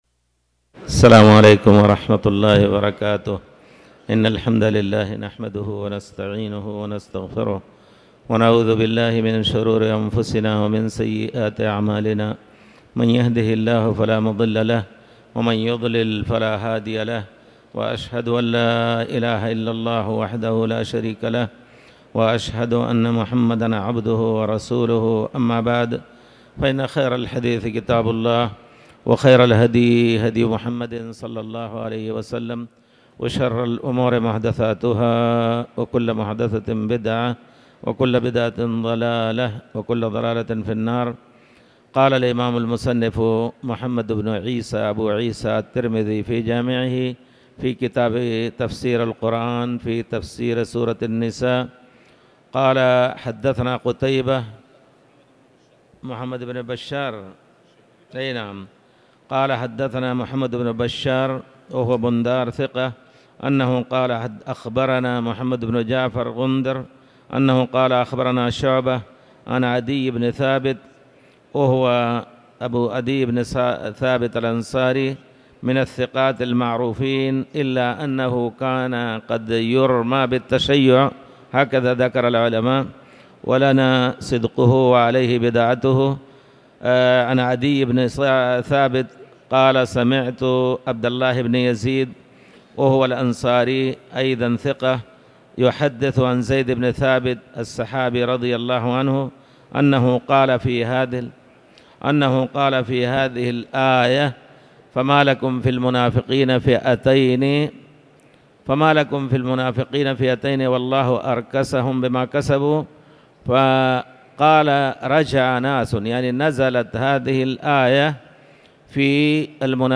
تاريخ النشر ٢٣ محرم ١٤٤٠ هـ المكان: المسجد الحرام الشيخ